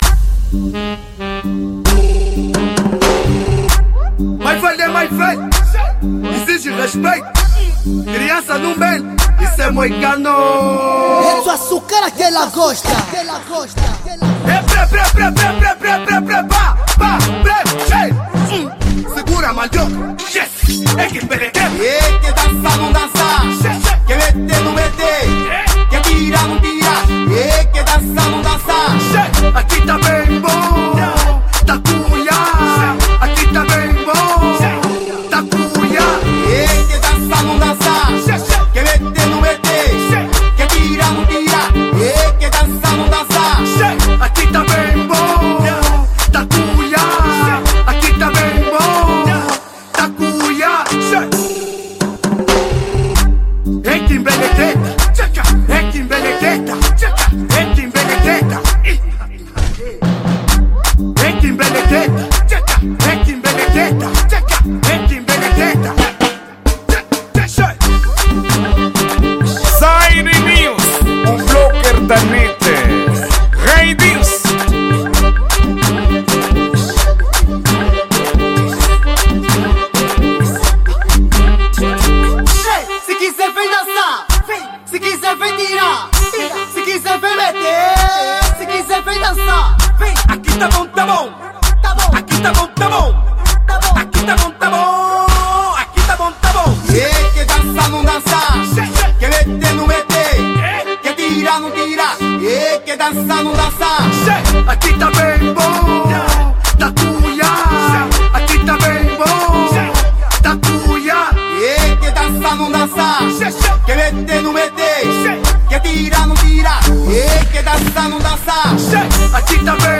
Gênero:Afro House